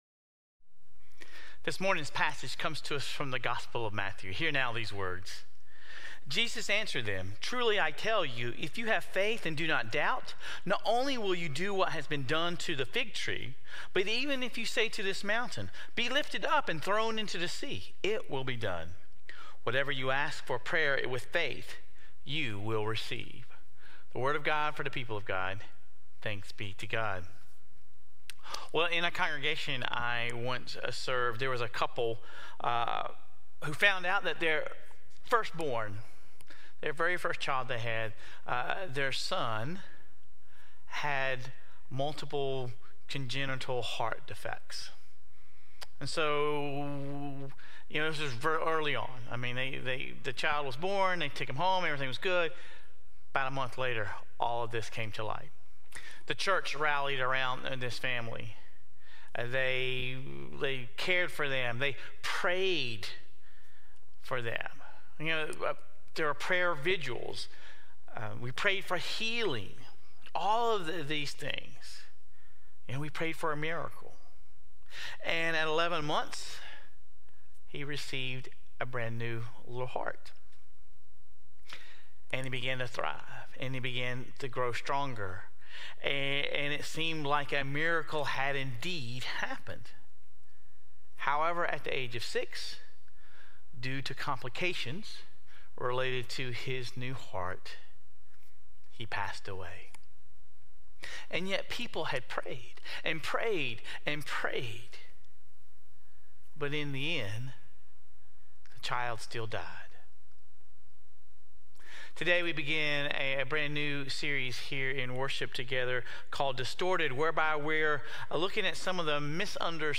Sermon Reflections: What is your understanding of the purpose of prayer?